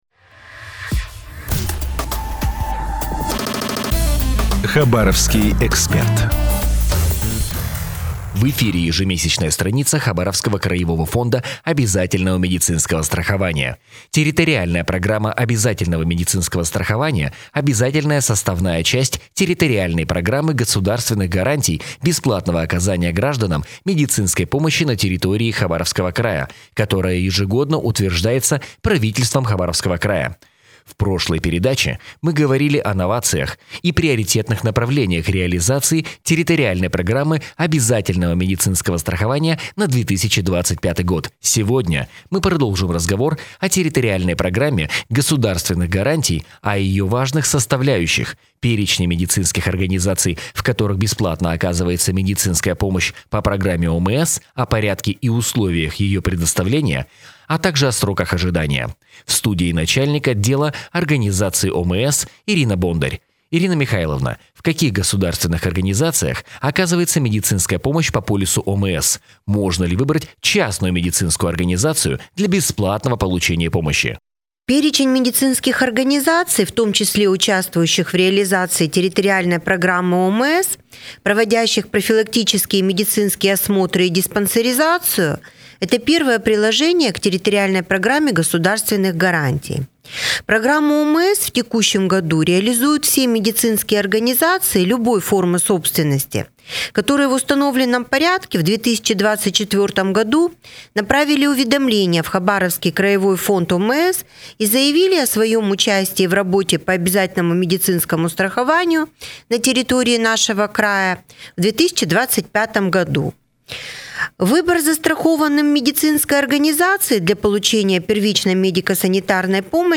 Выступление на радио